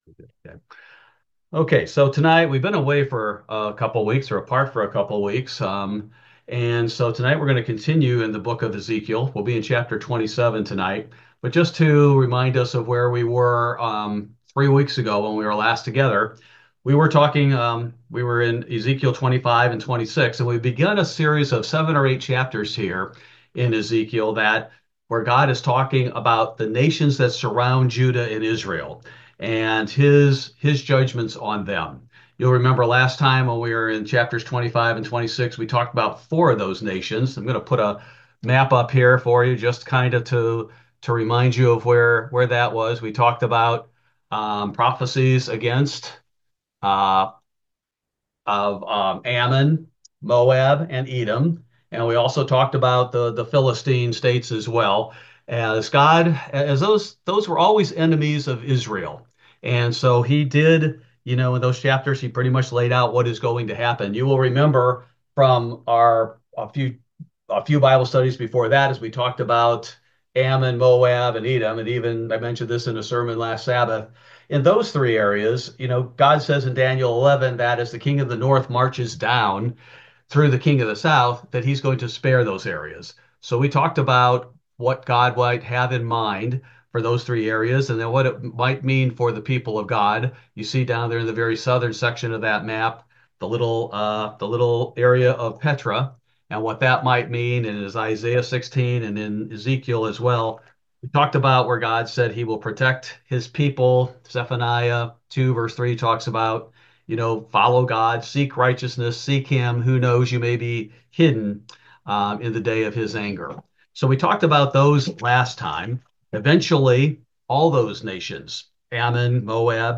Ezekiel Bible Study: January 8, 2025